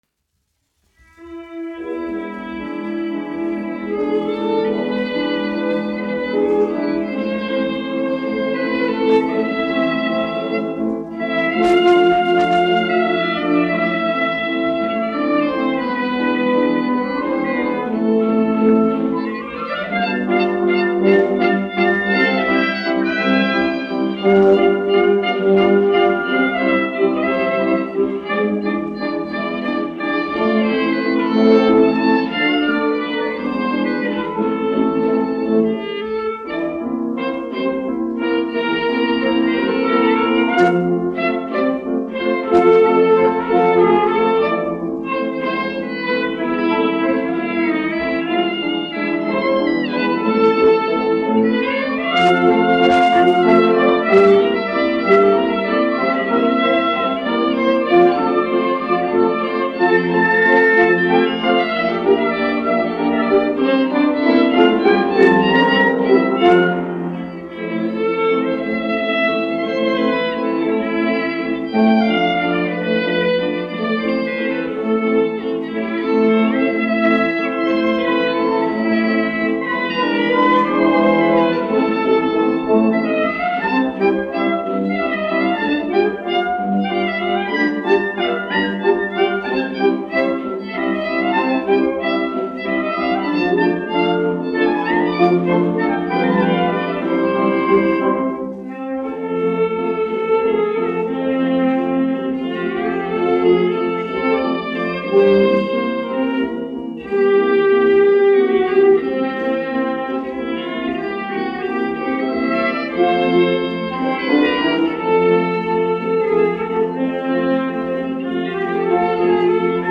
Donavas viļņi : valsis
Marcella (mūzikas grupa), izpildītājs
1 skpl. : analogs, 78 apgr/min, mono ; 25 cm
Valši
Skaņuplate